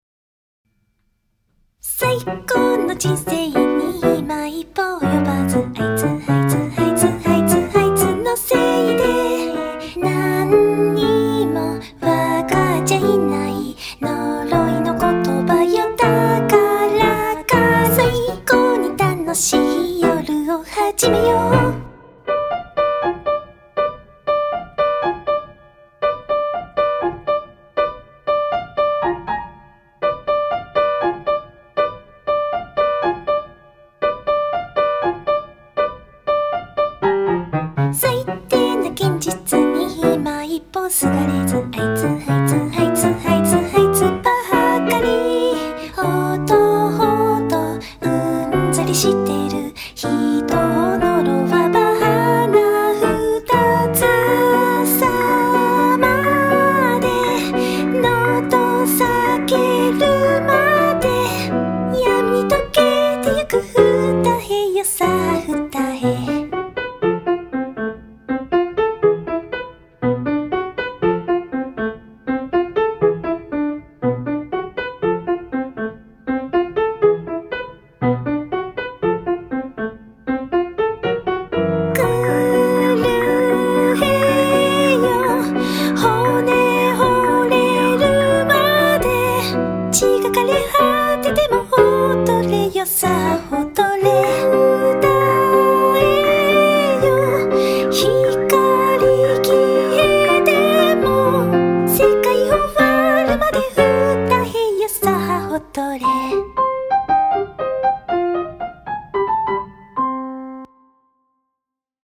【Vocal】 mp3 DL ♪
BPM 120